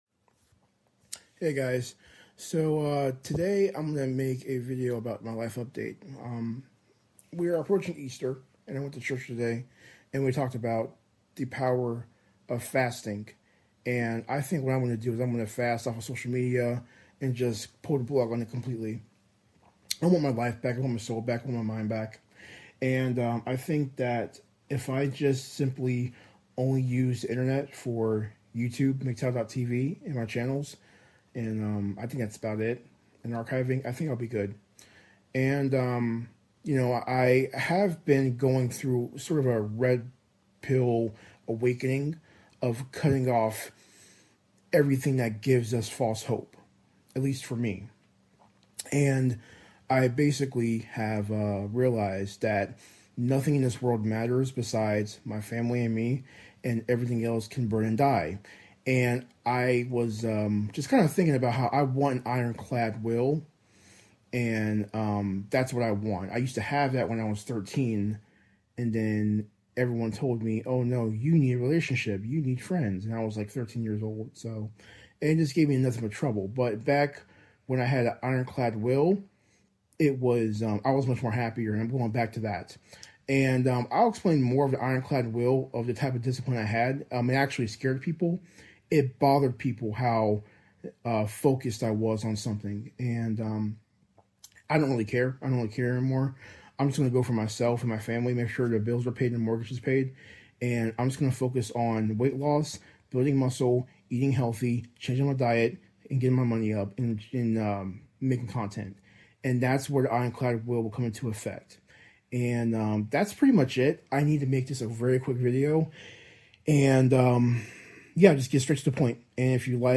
i think the audio is off, but THANKS FOR THE COMMENTS! yeah I'm trying to get into manga LOL beserk is cool though :D